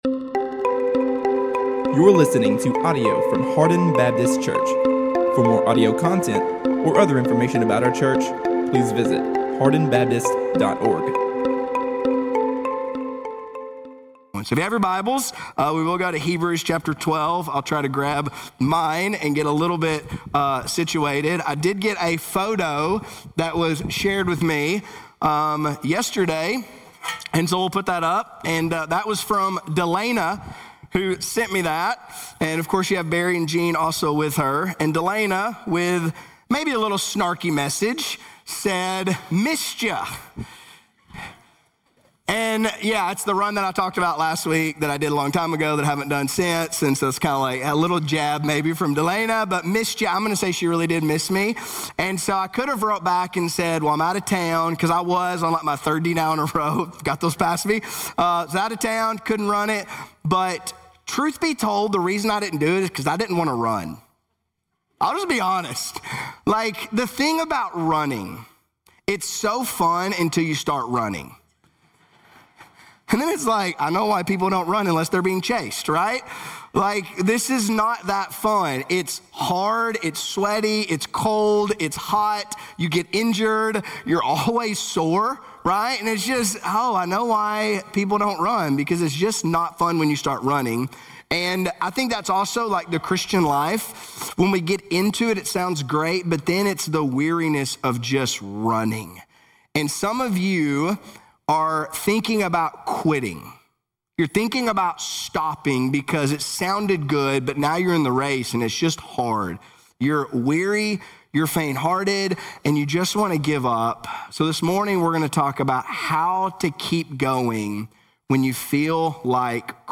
A message from the series "Hebrews 2025."